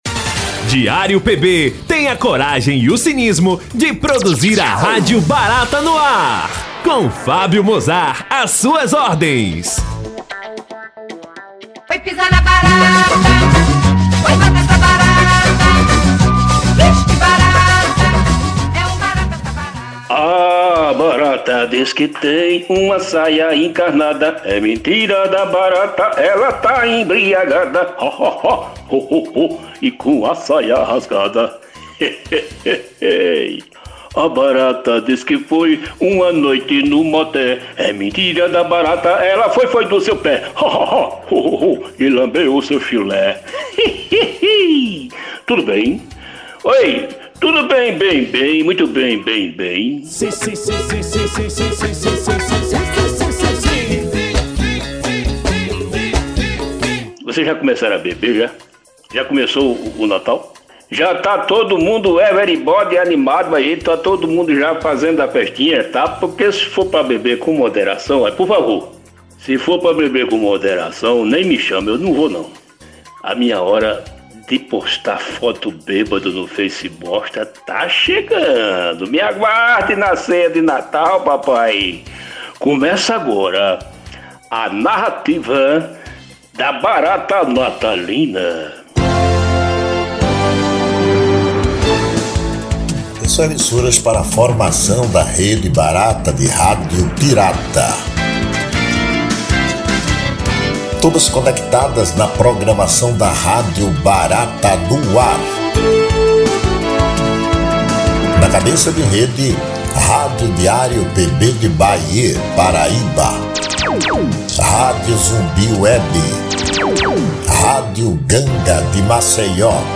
O objetivo do programa é abordar assuntos do cotidiano, comentar de maneira bem humorada, os acontecimentos da semana, de forma irreverente e leve, sempre com boas piadas.